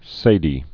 (sādē)